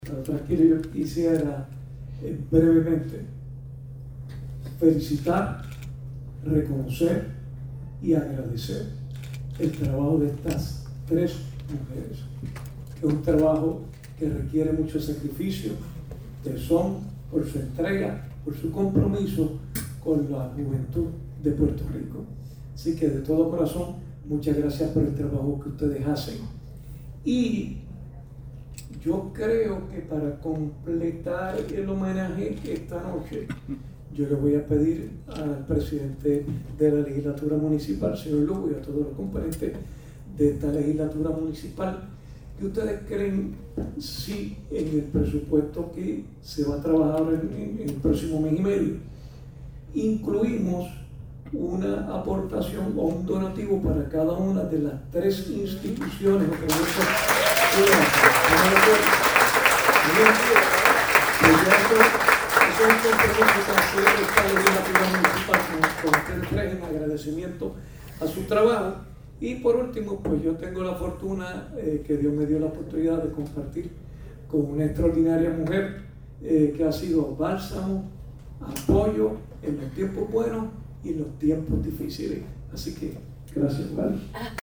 En el marco de la Semana de la Mujer la Legislatura Municipal de Bayamón rindió homenaje a cuatro mujeres cuya trayectoria refleja compromiso, liderazgo y vocación de servicio - Foro Noticioso Puerto Rico